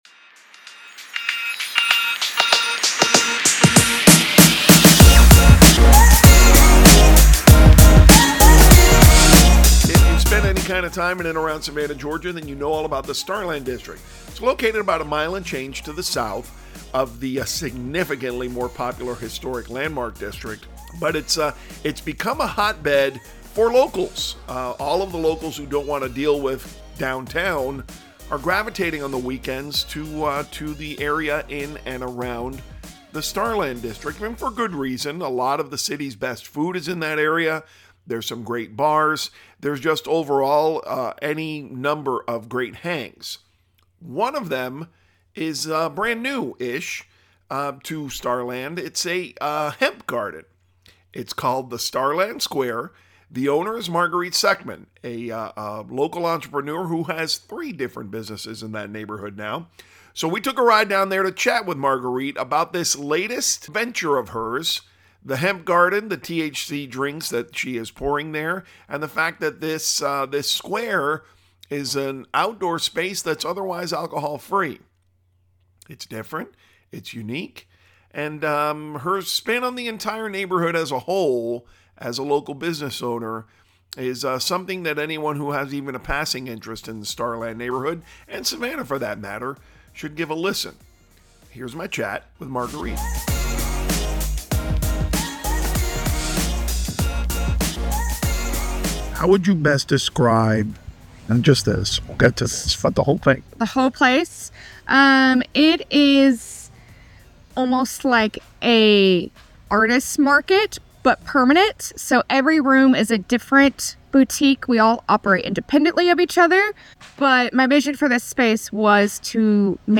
Podcast: A chat with a Starland District Business Owner